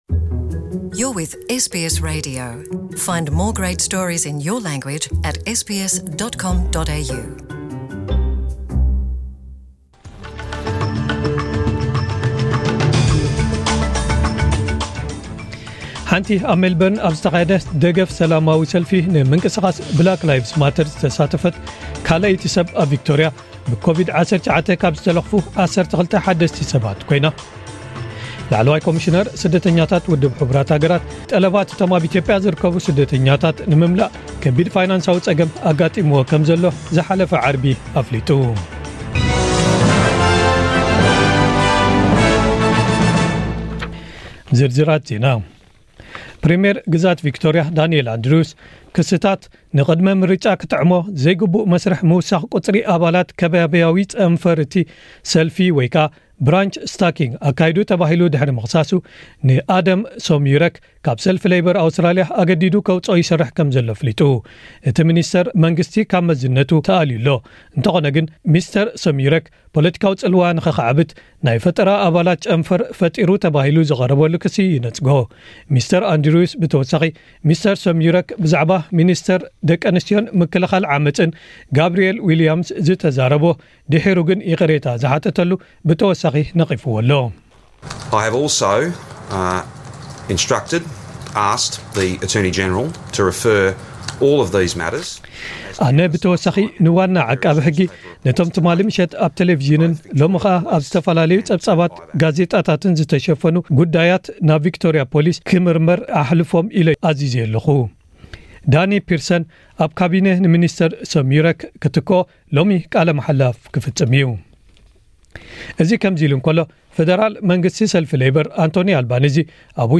ዕለታዊ ዜናታት ኤስቢኤስ ትግርኛ (15/06/2020) ላዕለዋይ ኮሚሽነር ስደተኛታት ው. ሕ. ሃ. (UNHCR) ጠለባት'ቶም ኣብ ኢትዮጵያ ዝርከቡ ስደተኛታት ንምምላእ ከቢድ ፋይናንሳዊ ጸገም ኣጋጢምዎ ከምዘሎ ኣፍሊጡ። ፕረዚደንት ሶማሊላንድ ሙሰ ቢሂ ዓብዲ ዝመርሖ ልኡኽ ናይ ስራሕ ዑደት ንምፍጻም ሰንበት ጂቡቲ ኣትዩ። ሓንቲ ኣብ መልበርን ኣብ ዝተኻየደ ደገፍ ሰልፊ ንምንቅስቓስ ብላክ ላይቭስ ማተር ዝተሳተፈት ካልኣይቲ ሰብ ኣብ ቪክቶርያ ብኮቪድ-19 ካብ ዝተለኽፉ 12 ሓደስቲ ሰባት ኮይና።